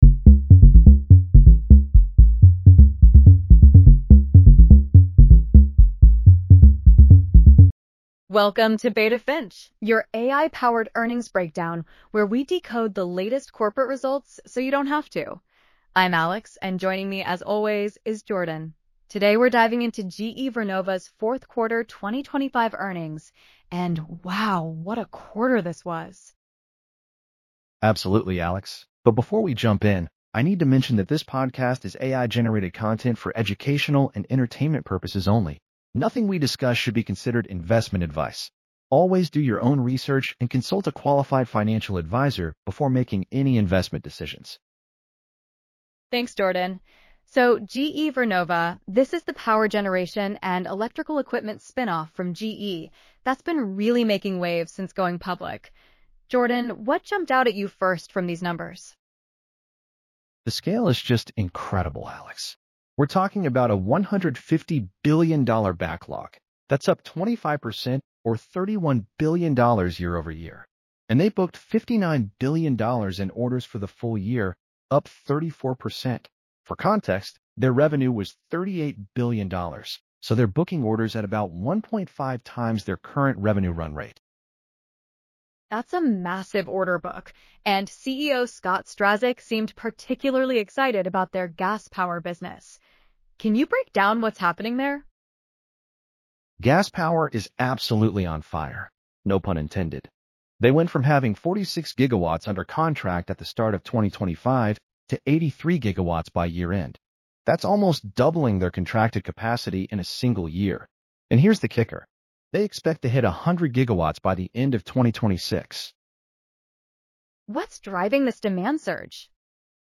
Beta Finch Podcast Script - GE Vernova Q4 2025 Earnings